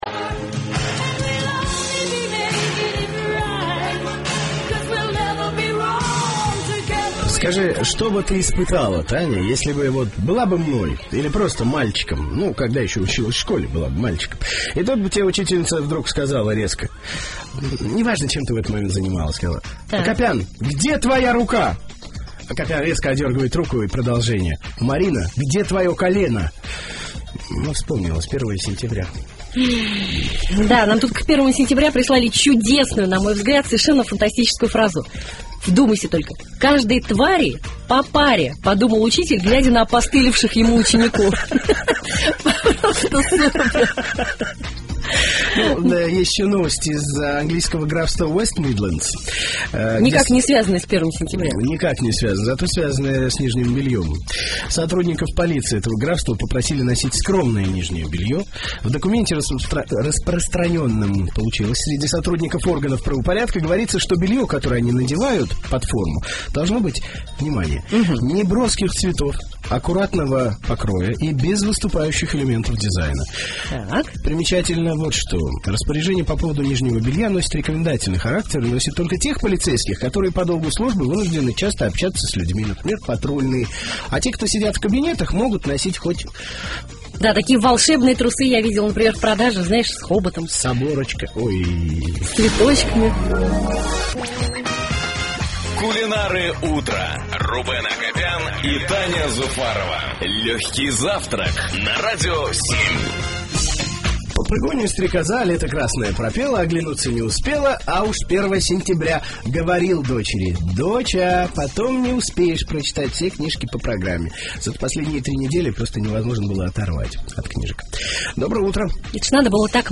Утреннее шоу "Легкий завтрак" на "Радио 7". Запись эфира.
Эфир от 1 сентября 2010 года, последний час.